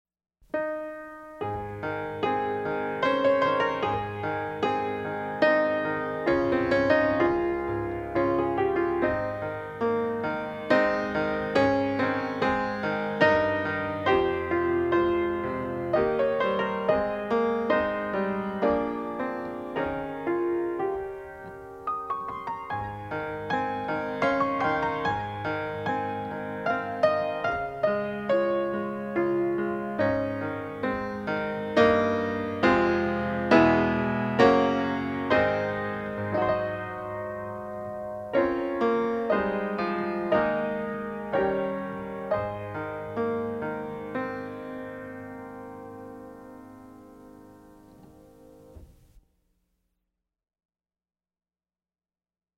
DIGITAL SHEET MUSIC - PIANO SOLO